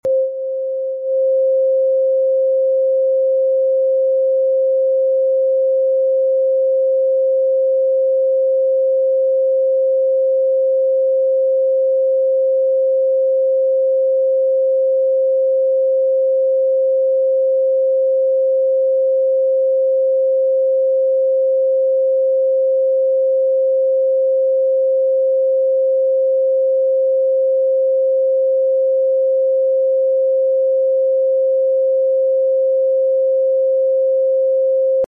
This is 528 Hz Frequency! sound effects free download